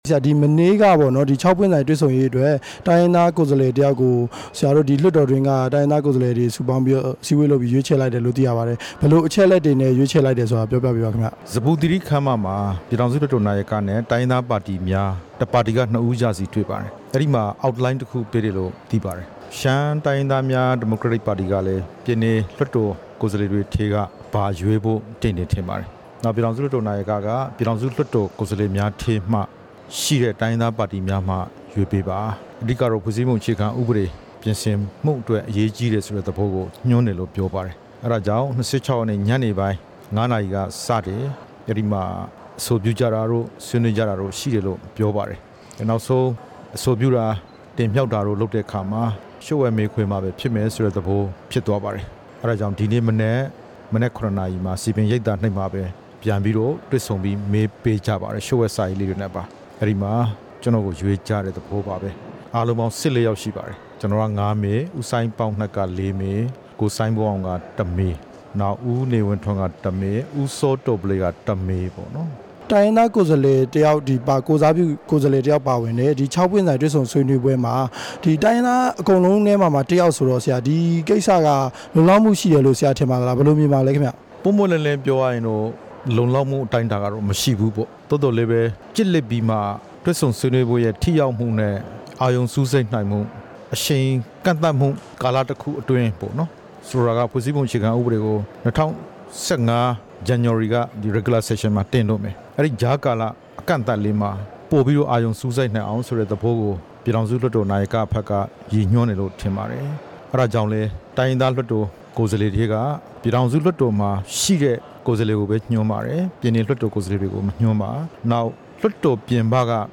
ဒေါက်တာ အေးမောင်နဲ့ မေးမြန်းချက်